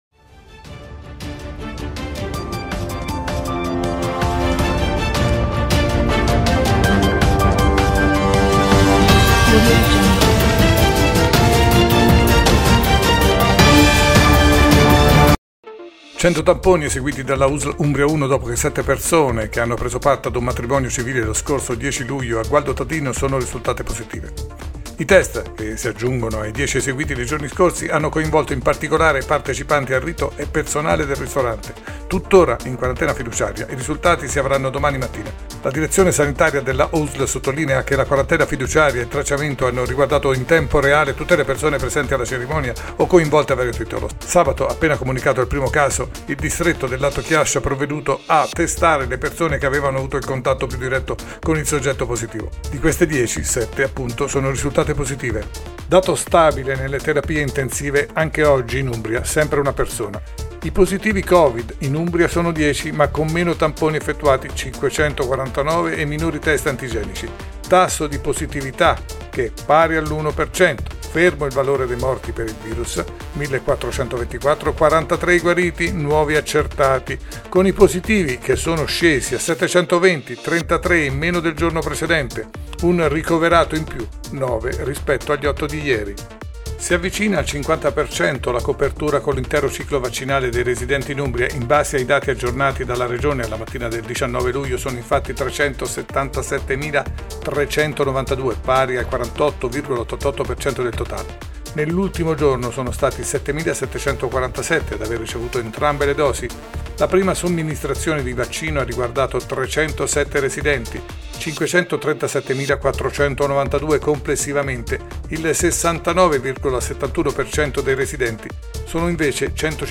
Tg dell’Umbria, Radiogiornale della sera, ultime notizie 19.07.2021